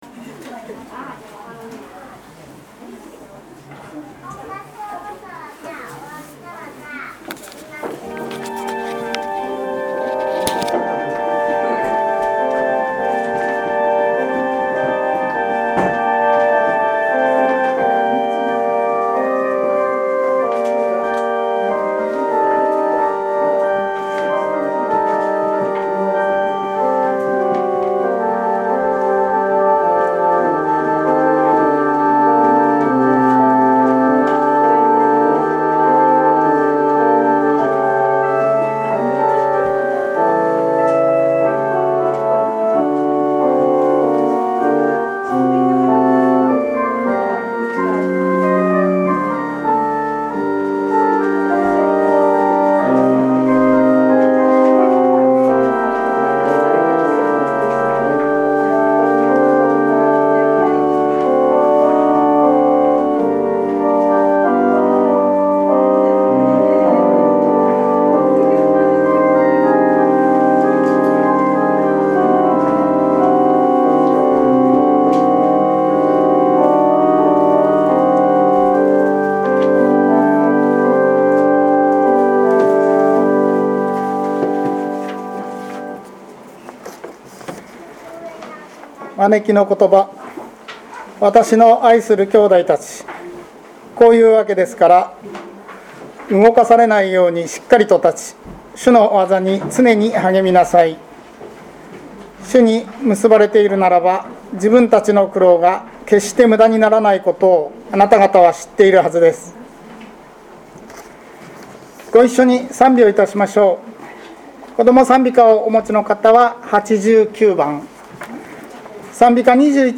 聖日礼拝のご案内（イースター礼拝） – 日本基督教団 花小金井教会
2022年4月17日 10:30 礼拝式次第はこちらをクリック 説教「イエス様と一緒に、朝ごはん！」 ヨハネによる福音書２１：１〜１４（新約ｐ211）